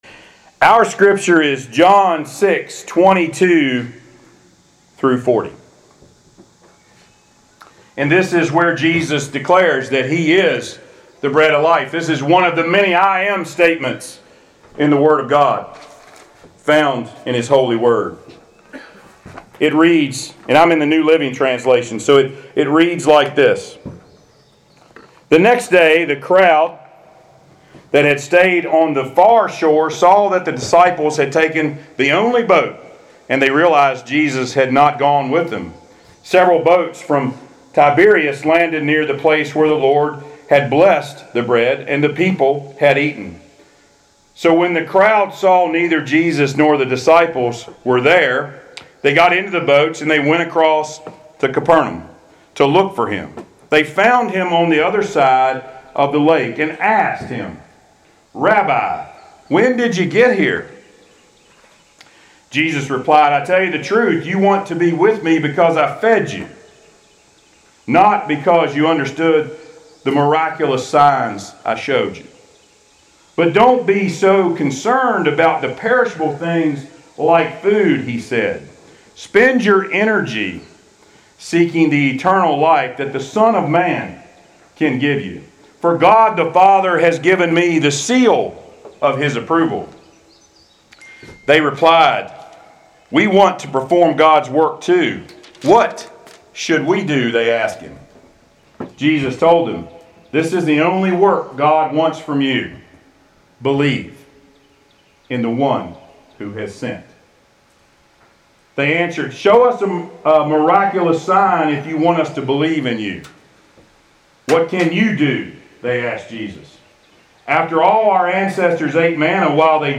Passage: John 6: 22-40 Service Type: Sunday Worship